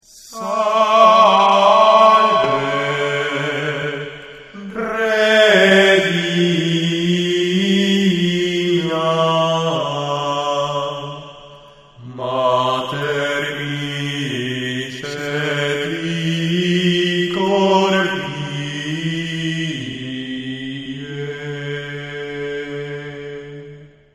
Dúo vocal a capella.
voz
canto
dúo
gregoriano